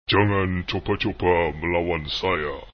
1 channel
2_taunt_jangan.wav.mp3